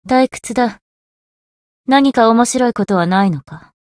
灵魂潮汐-萨缇娅-问候-不开心.ogg